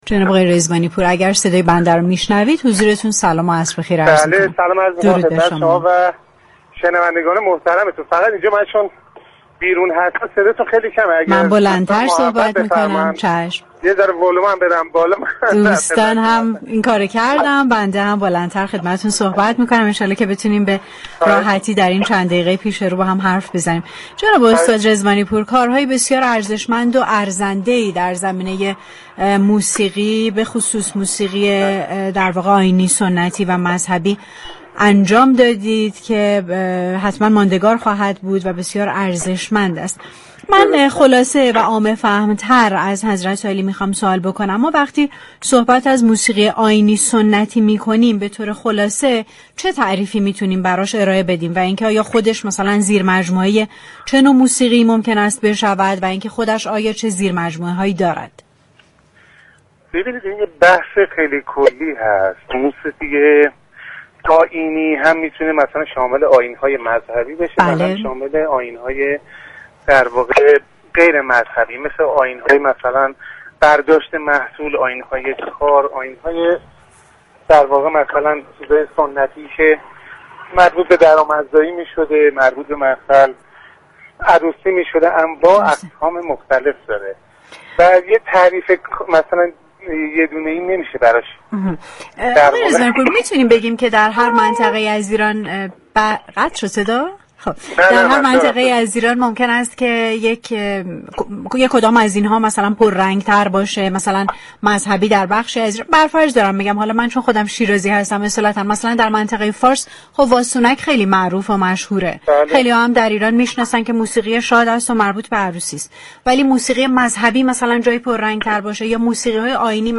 در گفتگوی تلفنی با برنامه پشت صحنه